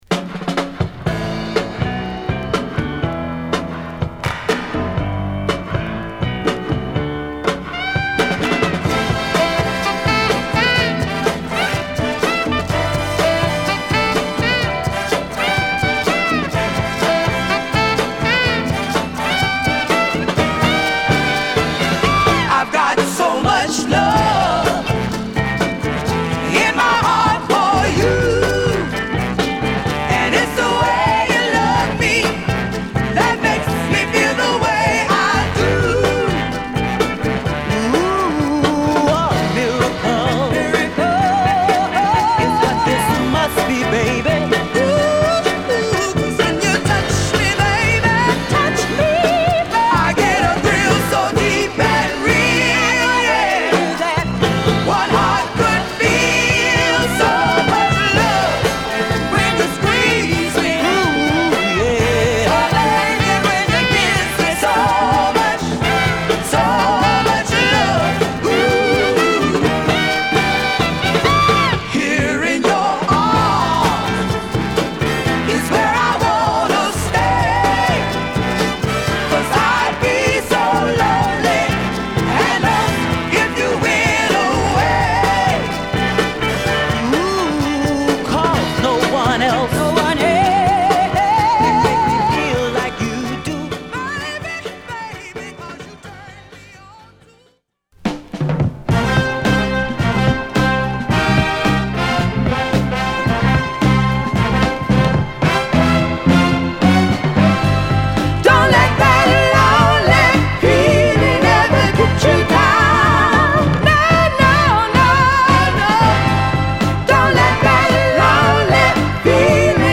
フロリダ発の男女混合ヴォーカル・グループ